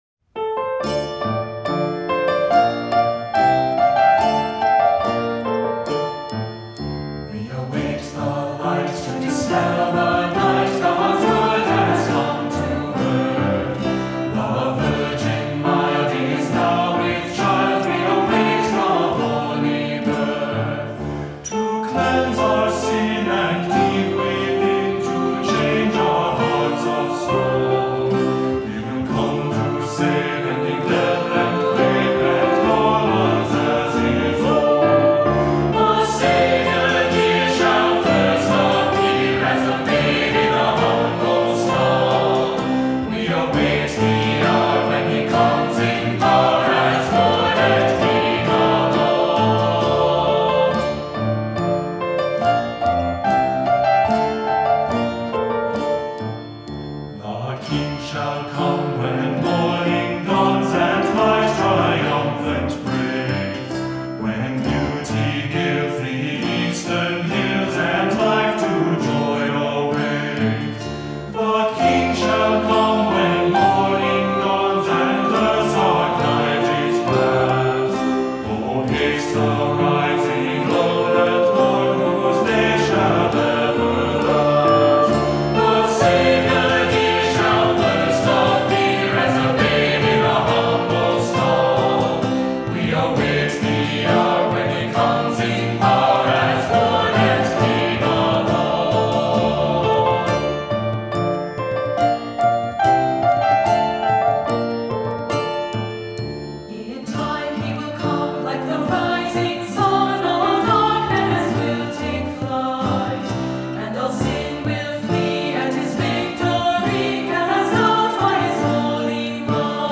Voicing: "SATB", "Cantor", "Assembly"